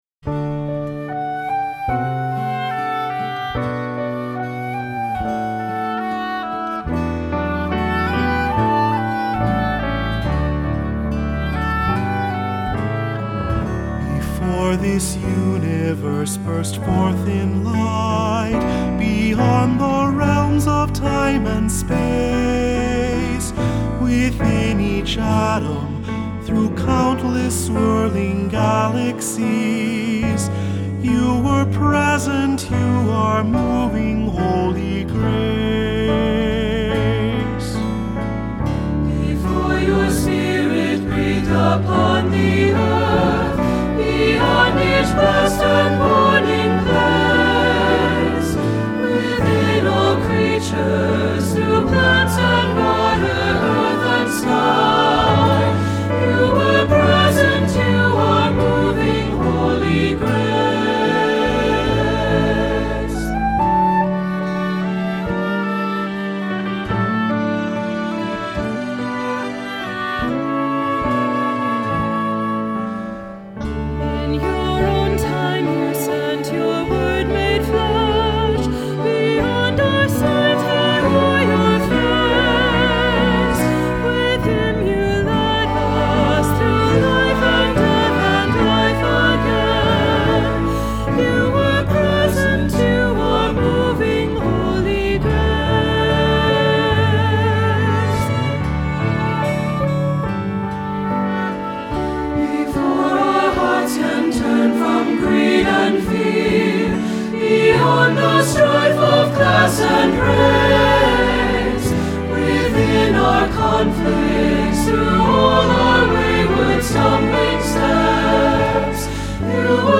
Accompaniment:      Keyboard, C Instrument I;C Instrument II
Music Category:      Christian
The melody moves fluidly and is easily memorized.